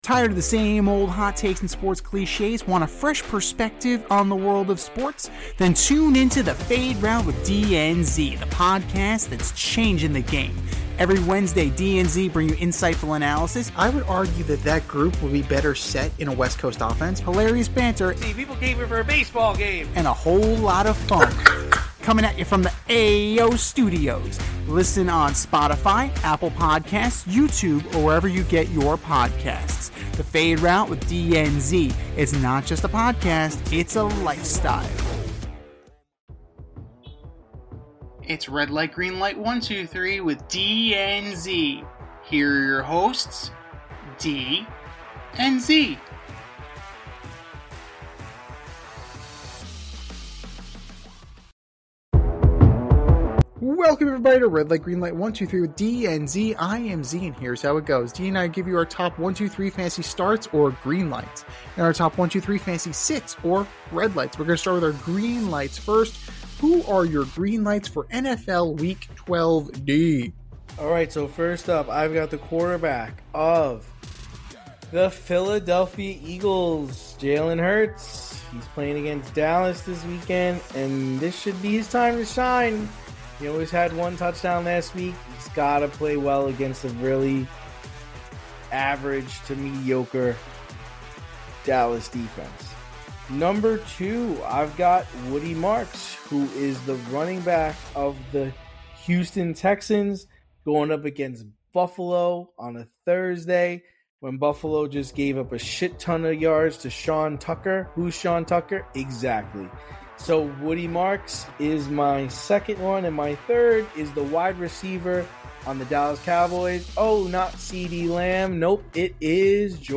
two veteran sports aficionados and lifelong friends, as they dissect the week’s top stories with wit and a touch of New York flair.